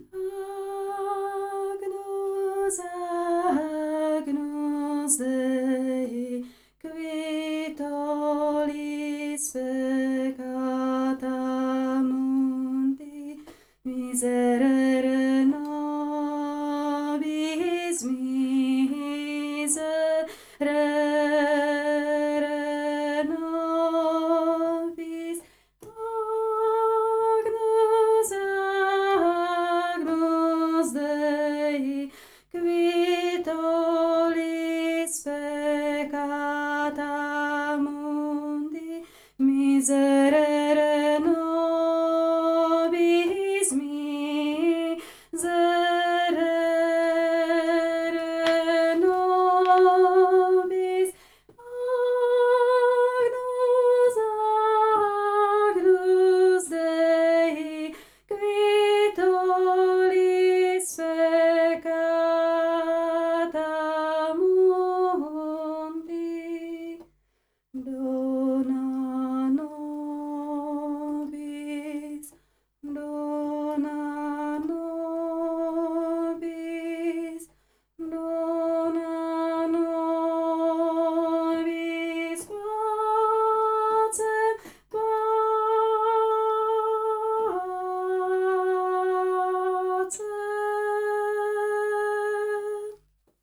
agnus_dei-alt.mp3